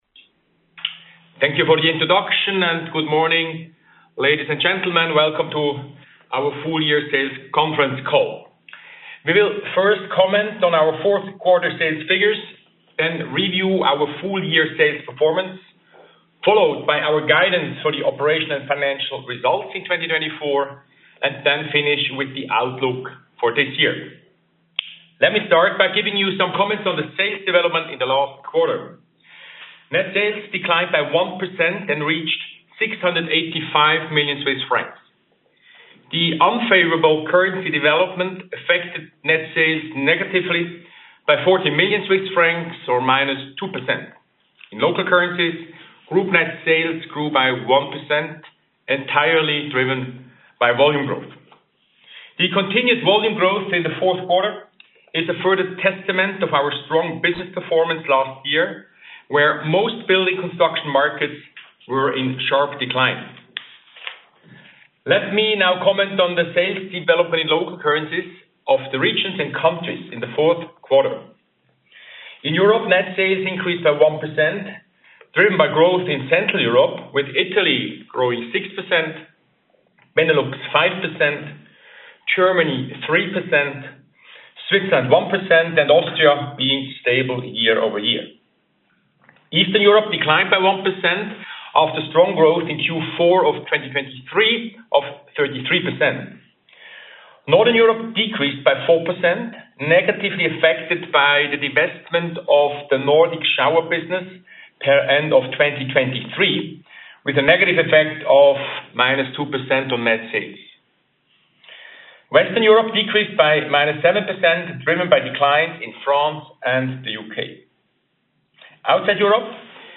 recording-geberit-confcall-fi-2024.mp3